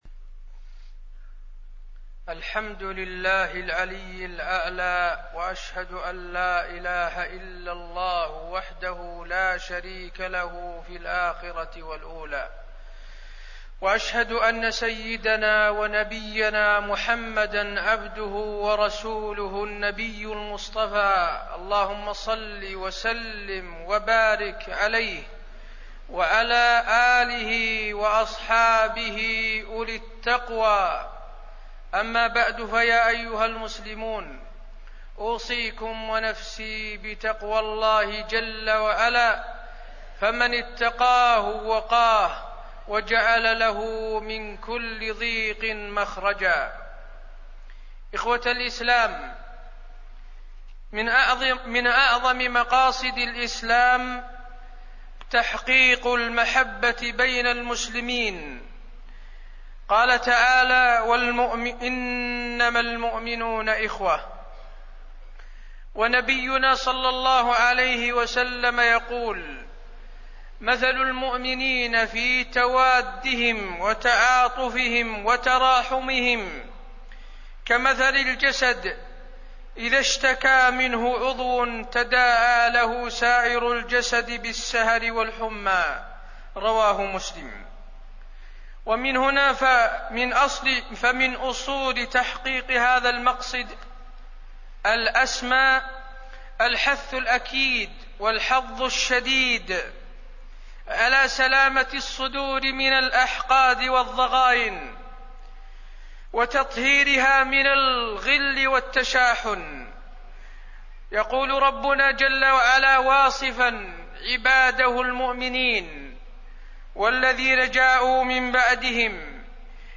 تاريخ النشر ٢٥ شعبان ١٤٣١ هـ المكان: المسجد النبوي الشيخ: فضيلة الشيخ د. حسين بن عبدالعزيز آل الشيخ فضيلة الشيخ د. حسين بن عبدالعزيز آل الشيخ الحب بين المسلمين